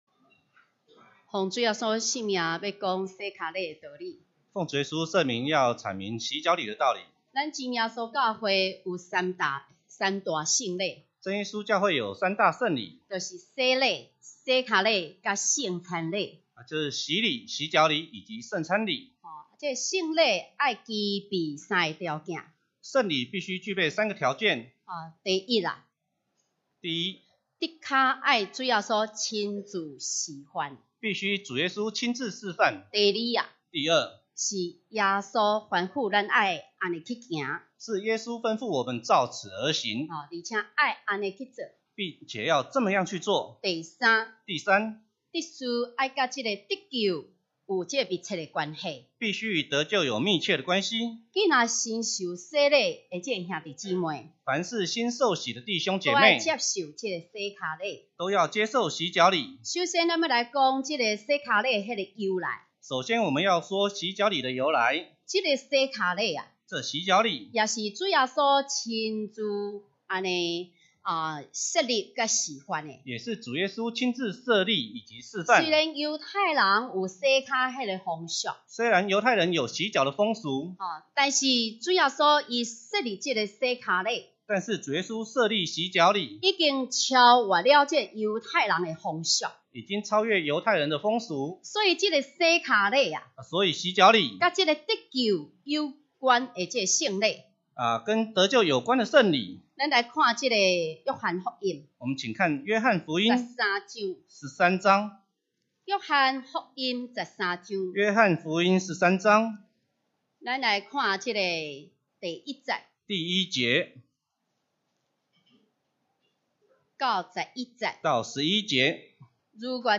2018年春季靈恩佈道會講道錄音已上線